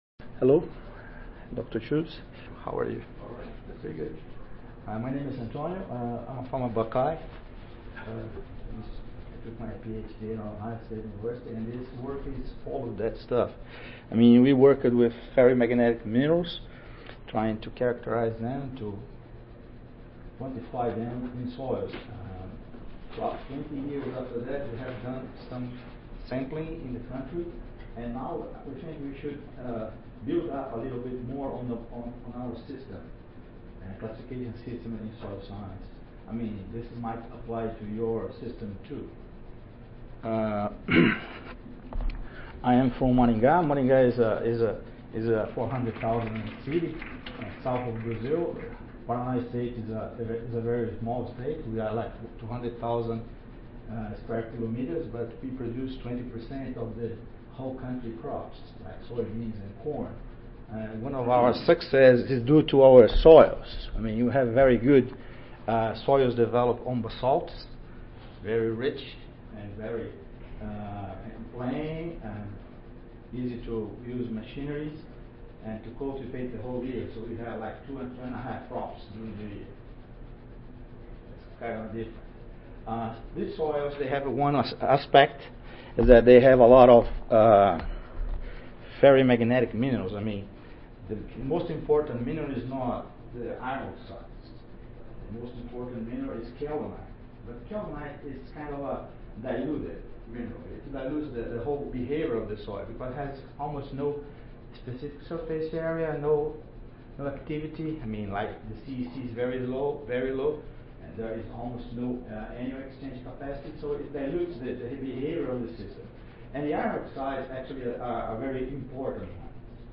See more from this Division: SSSA Division: Soil Mineralogy See more from this Session: Soil Mineralogy General Oral